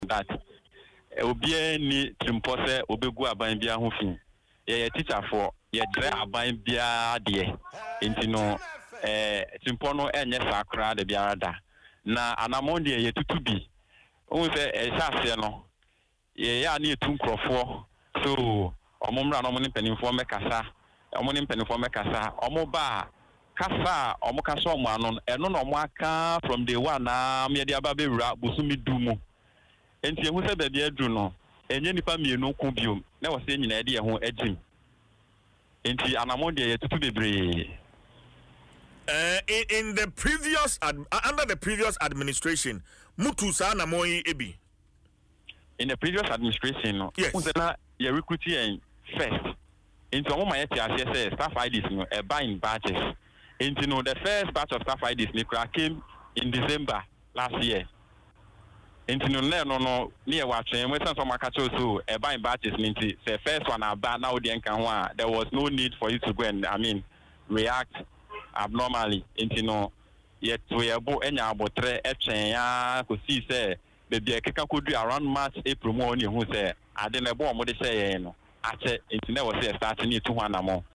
Speaking in an interview on Adom FM’s Dwaso Nsem, he explained that their actions are a result of frustration after exhausting several channels to get their concerns addressed.